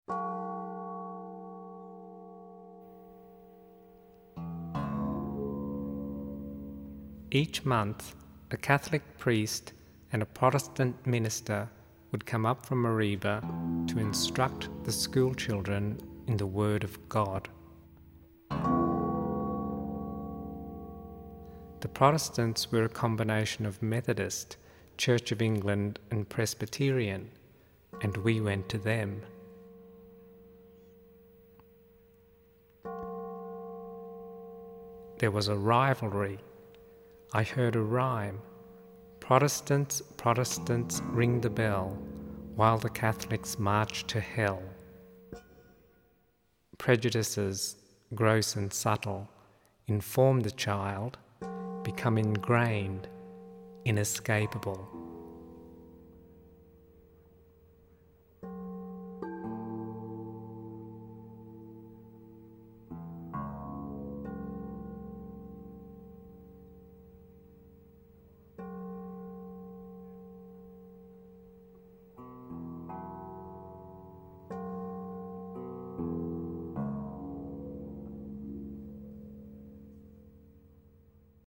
A lush musical painting of the Australian landscape.